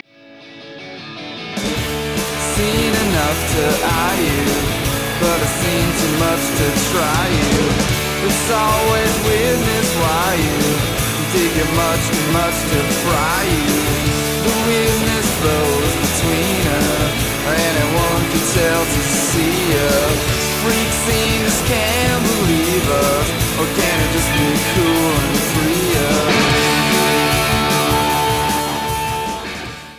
녹음 장소포트 아파치 스튜디오 (케임브리지, 매사추세츠주)
장르얼터너티브 록
인디 록
노이즈 록